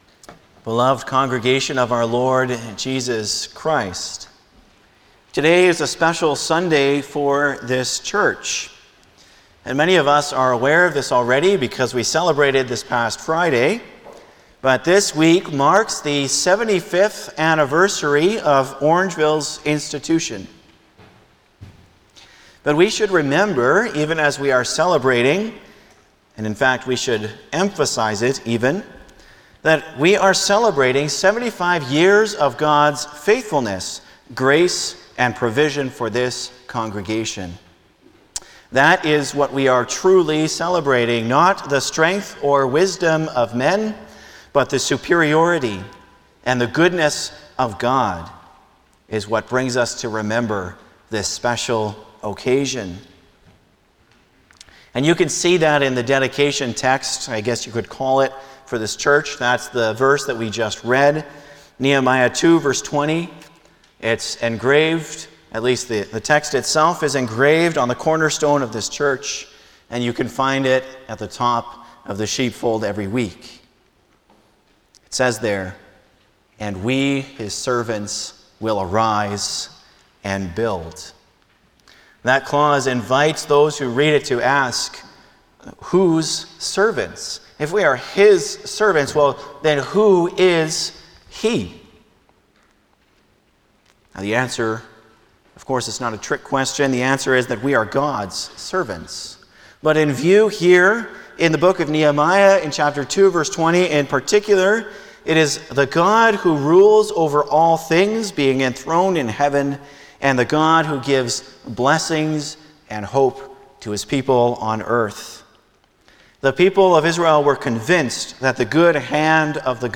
Passage: Nehemiah 2:20 Service Type: Sunday afternoon
07-Sermon.mp3